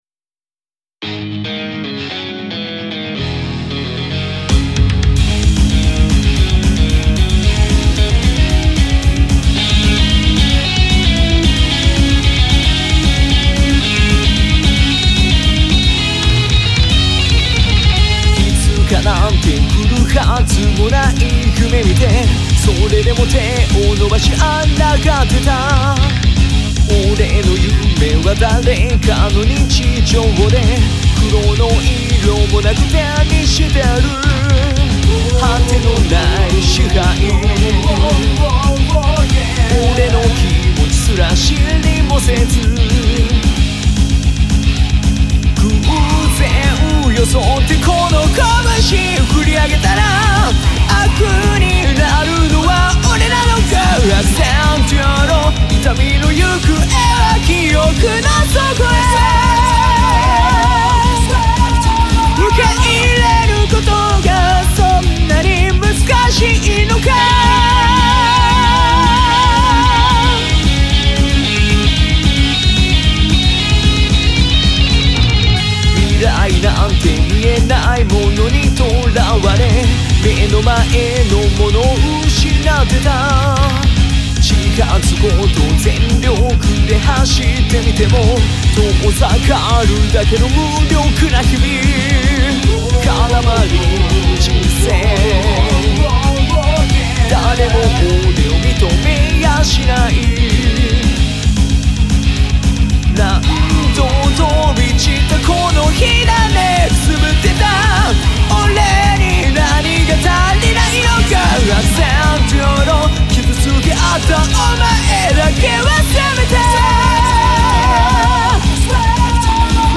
ロック
歌版　→歌詞はこちら